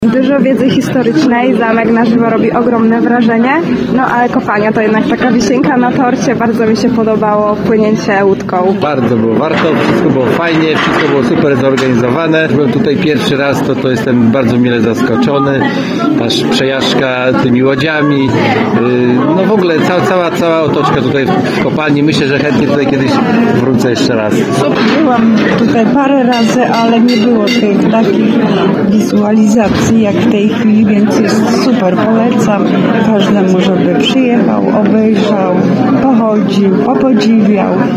– Było warto, bardzo było fajnie, wszystko było super zorganizowane, jestem tutaj pierwszy raz i jestem bardzo mile zaskoczony – mówi inny uczestnik wycieczki.